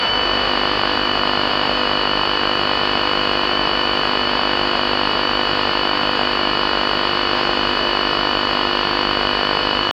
radio mute sound
radio-mute-sound-7l5bqv3e.wav